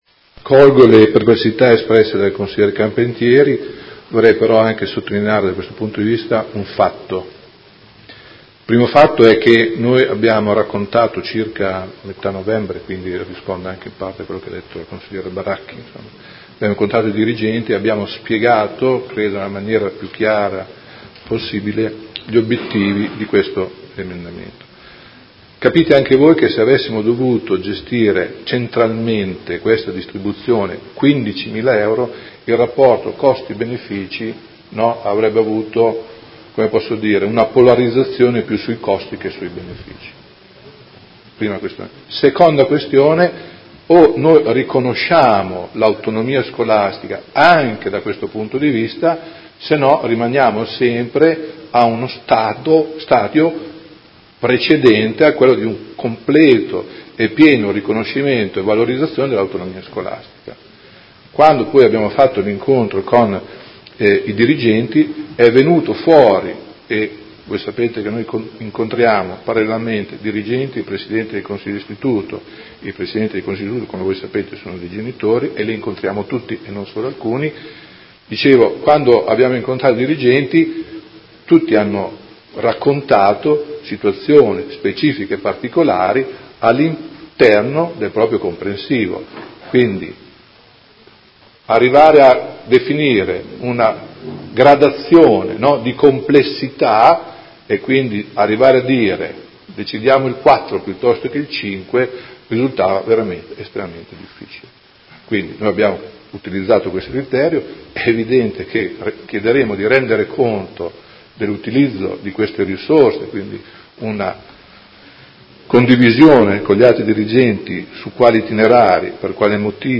Seduta del 10/01/2019 Conclusioni a dibattito. Interrogazione dei Consiglieri Baracchi e Carpentieri (PD) avente per oggetto: Utilizzo risorse emendamento al Bilancio di previsione 2018-2020